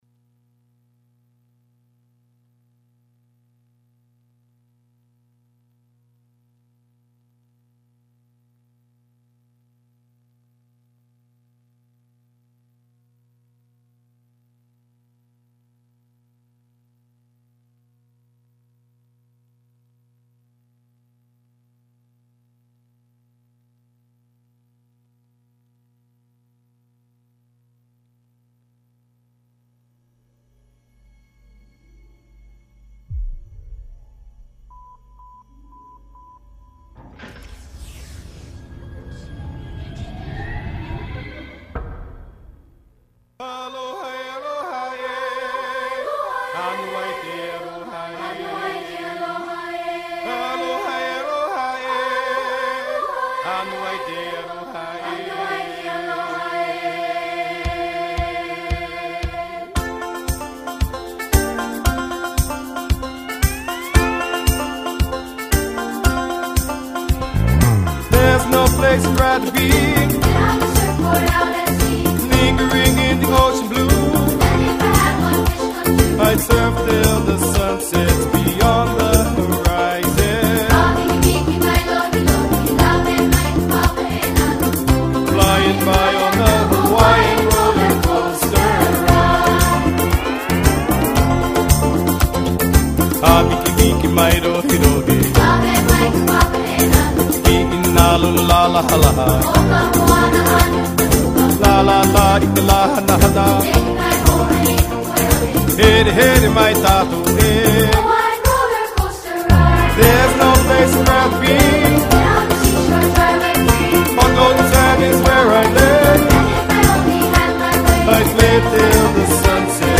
Youth Radio Raw is a weekly radio show produced by Bay Area high schoolers, ages 14-18.